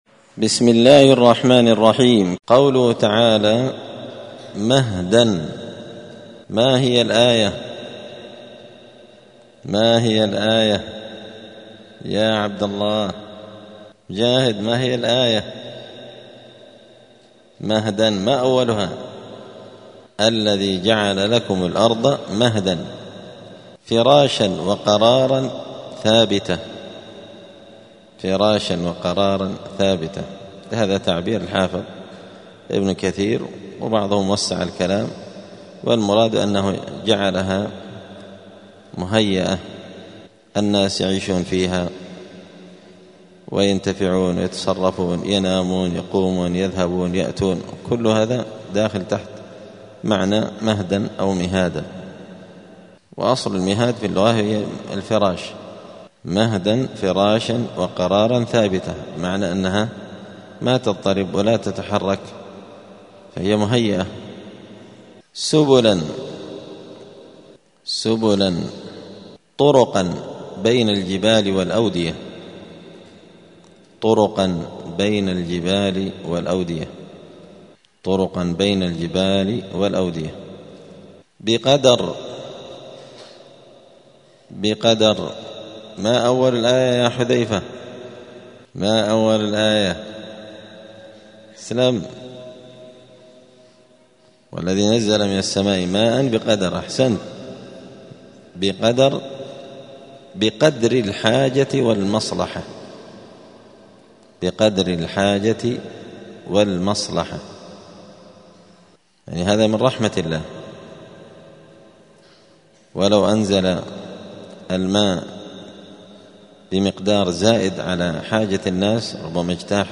*(جزء فصلت سورة الزخرف الدرس 233)*